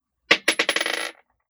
Bouncing Bullet 001.wav